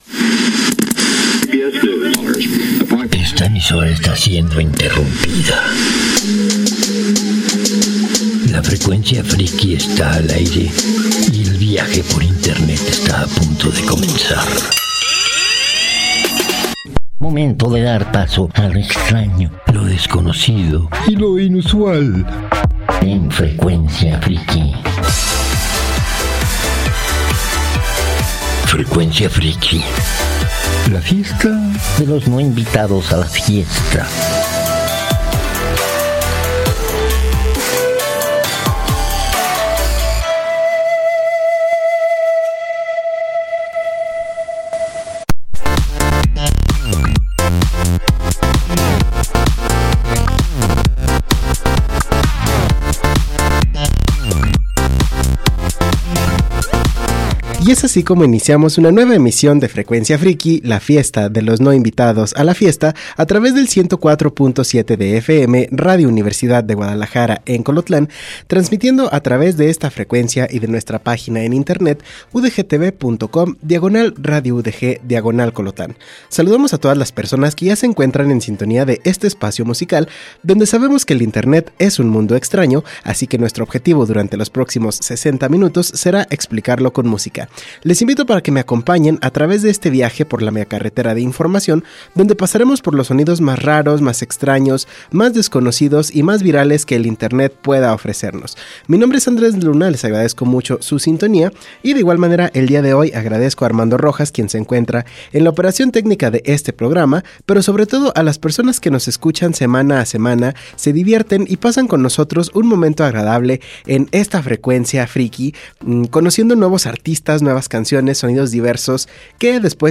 es un lugar donde nos encanta la música del mundo (pero sobretodo de China, Corea del Sur y Japón); es un programa para lo extraño, lo desconocido y lo inusual.